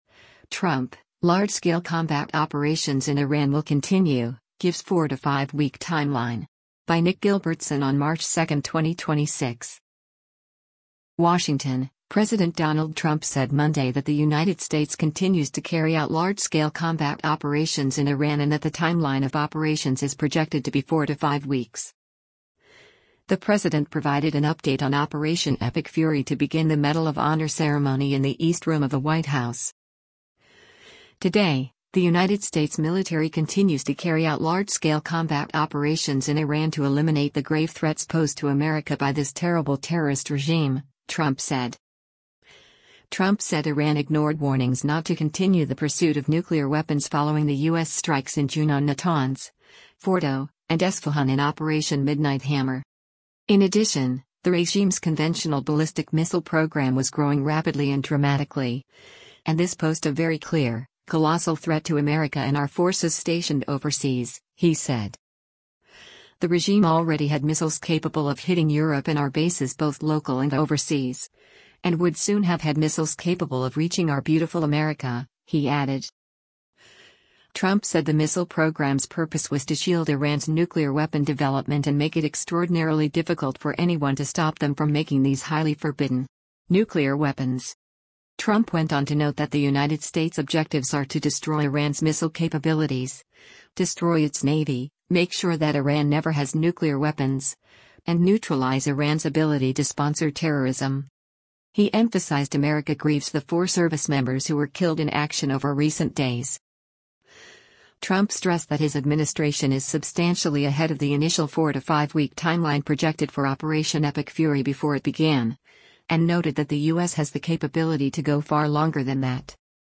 The president provided an update on Operation Epic Fury to begin the Medal of Honor ceremony in the East Room of the White House.